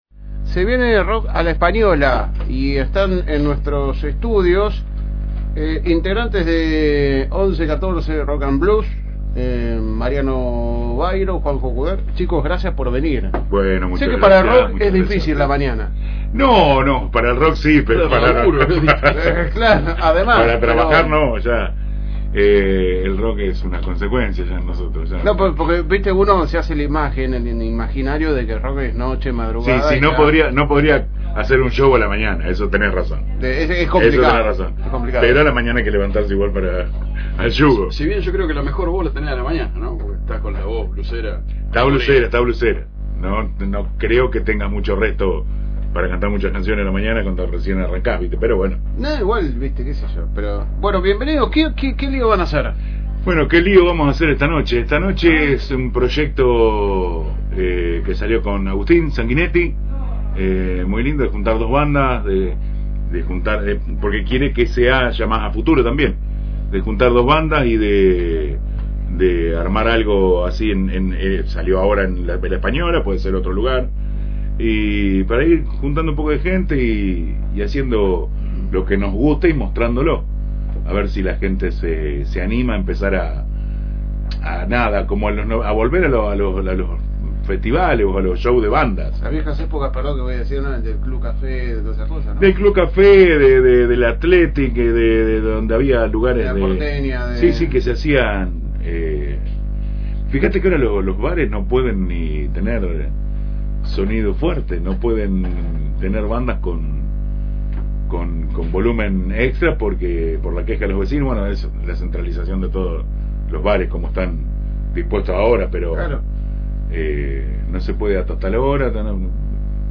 Durante la entrevista, los integrantes hicieron un repaso profundo sobre el circuito musical de la ciudad, analizando cómo han cambiado las dinámicas a la hora de componer, ensayar y consumir música en la actualidad.